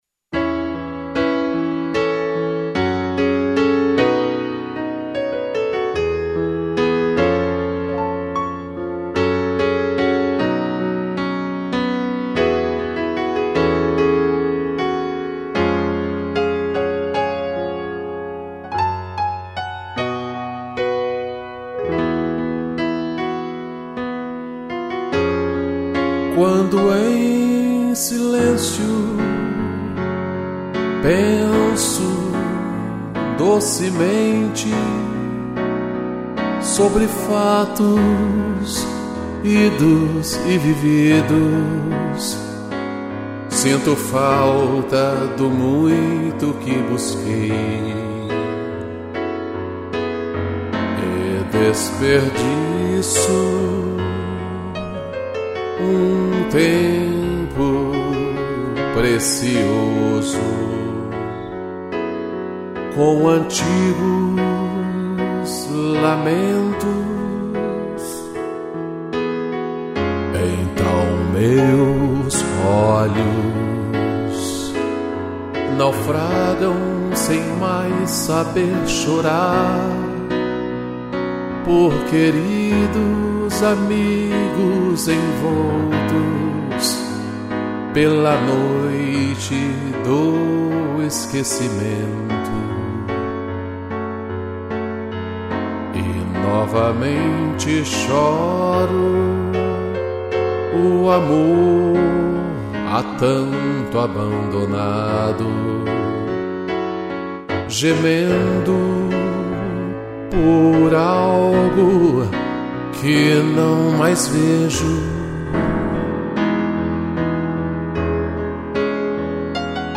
voz
piano